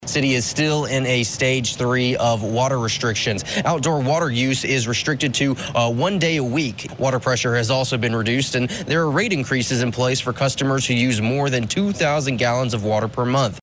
The City of Bartlesville is hoping for more rain as it struggles with its ongoing water shortage. News reporter